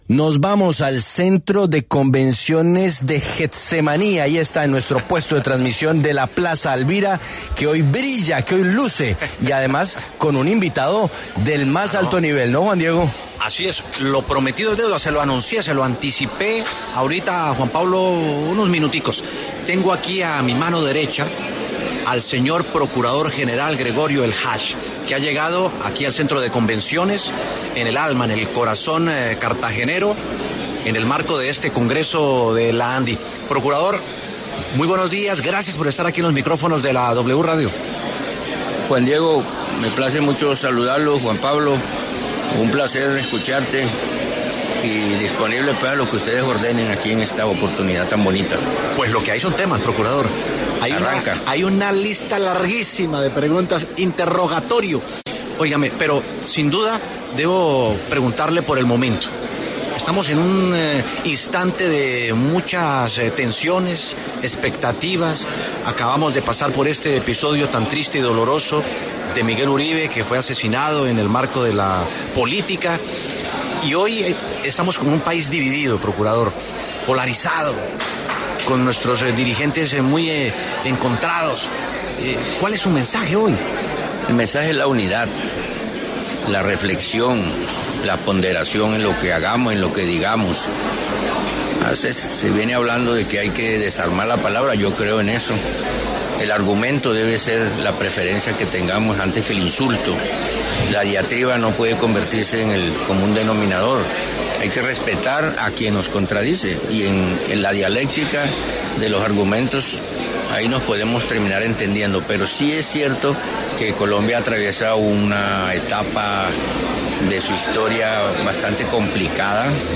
Procurador Gregorio Eljach habla desde el 10º Congreso Empresarial Colombiano